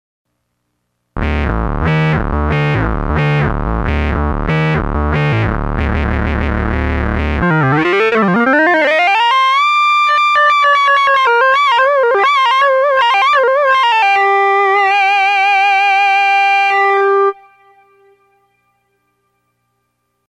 Ainsi que quelques extraits sonores de l’ARP Odyssey :
phasesynchronizedoscillator.mp3